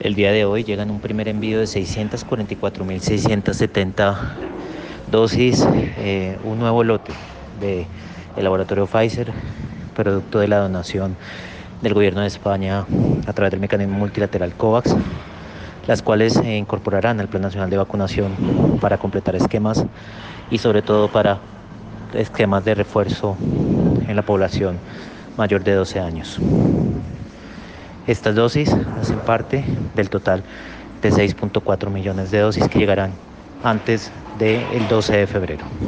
Audio de Germán Escobar, viceministro de Salud.